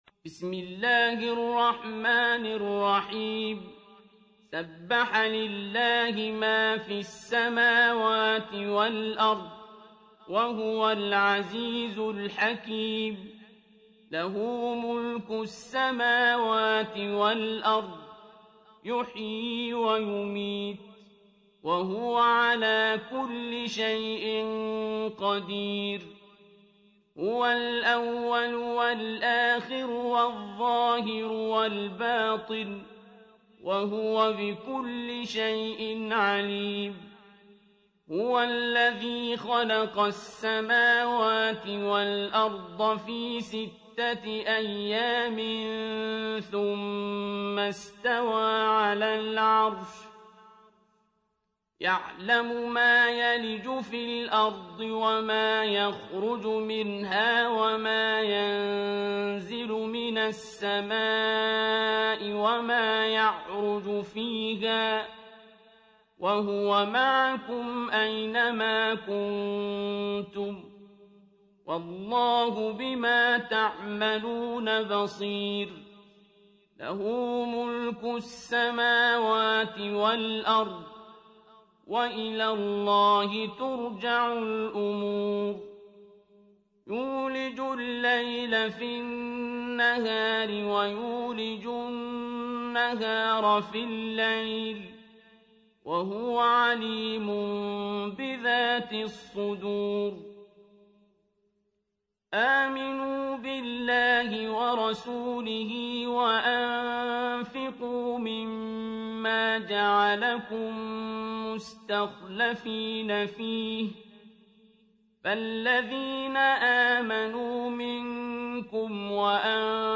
سورة الحديد | القارئ عبدالباسط عبدالصمد